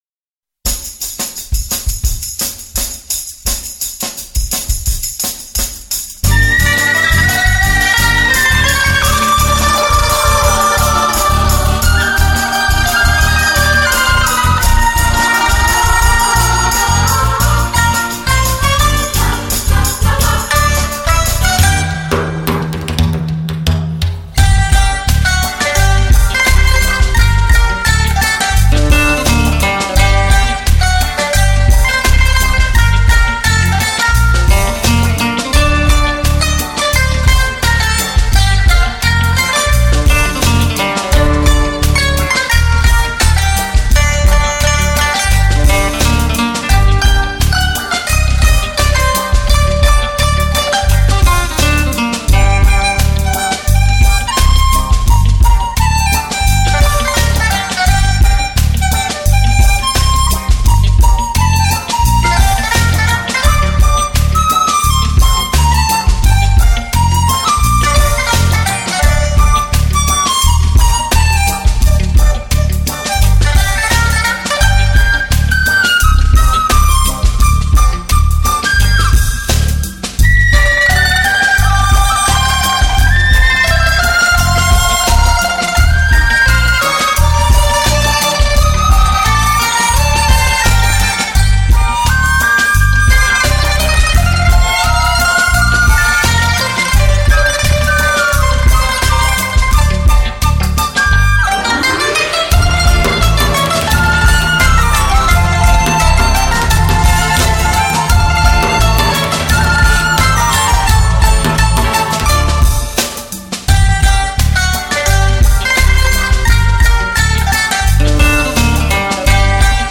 专辑格式：DTS-CD-5.1声道
HD直刻无损高音质音源技术